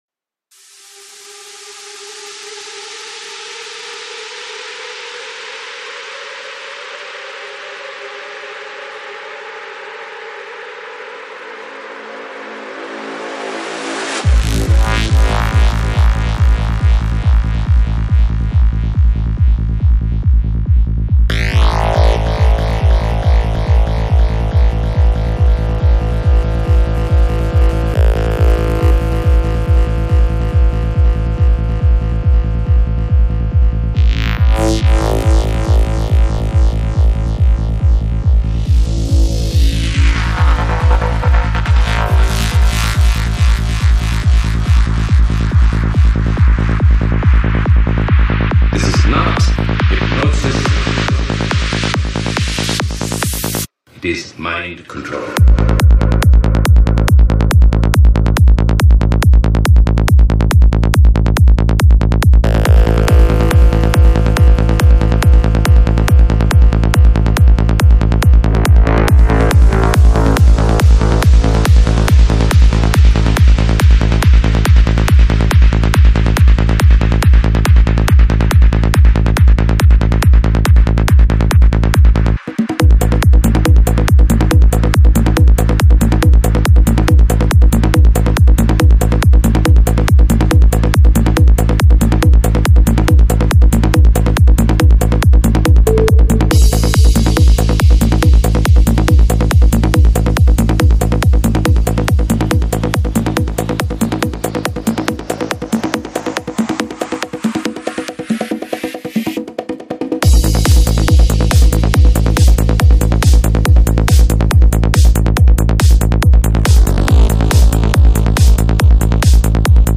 Жанр: Psychedelic
20:22 Альбом: Psy-Trance Скачать 7.65 Мб 0 0 0